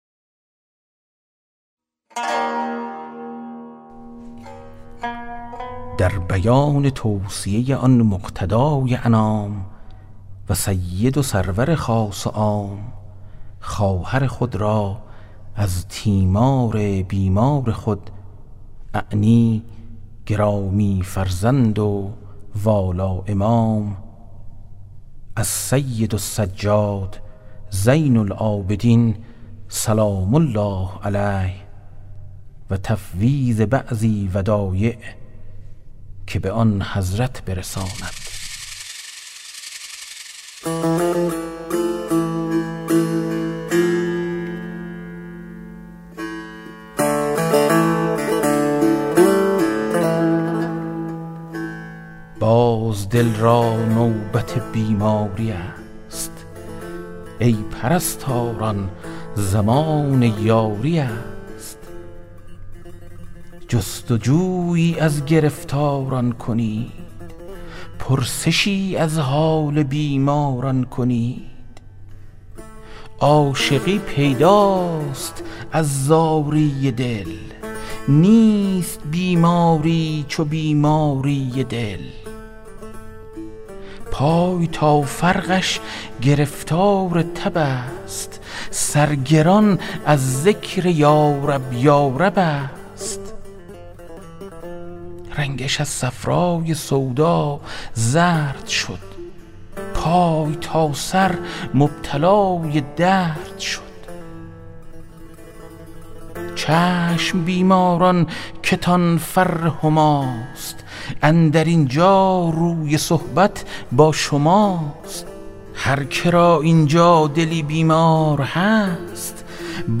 کتاب صوتی گنجینه الاسرار، مثنوی عرفانی و حماسی در روایت حادثه عاشورا است که برای اولین‌بار و به‌صورت کامل در بیش از ۴۰ قطعه در فایلی صوتی در اختیار دوستداران ادبیات عاشورایی قرار گرفته است.